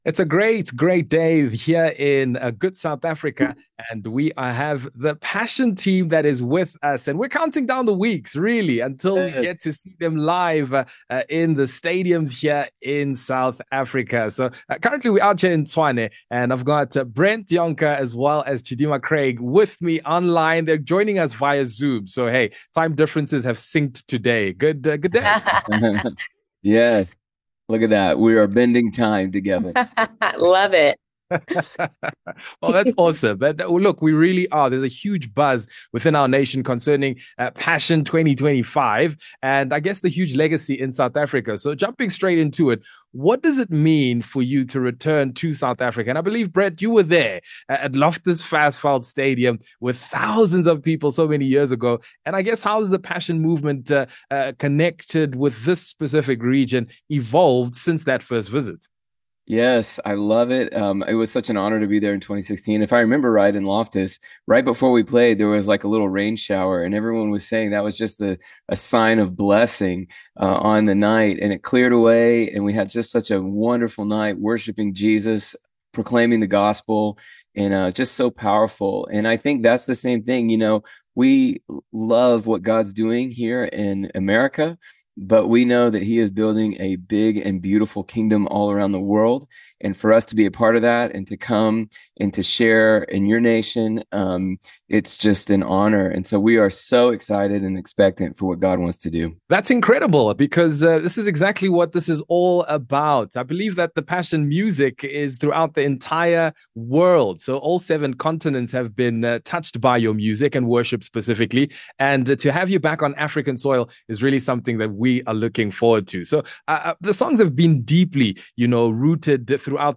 Get ready for an unforgettable discussion